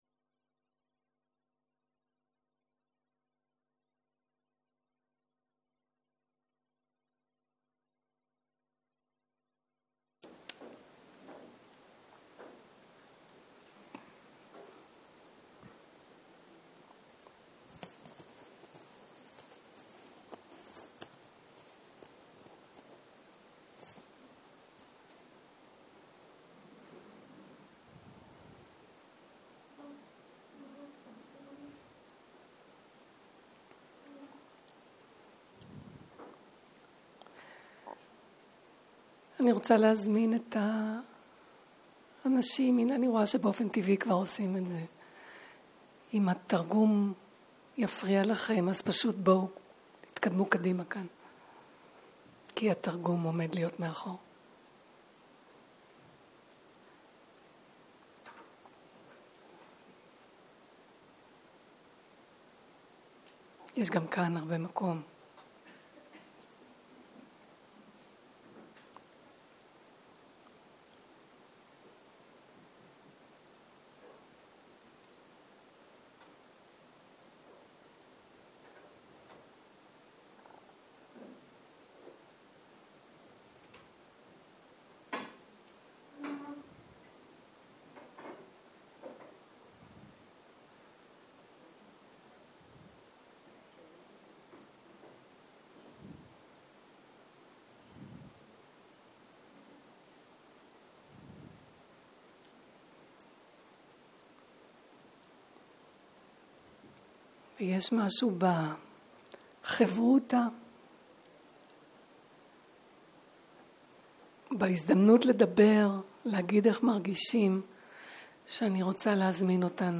Dharma type: Dharma Talks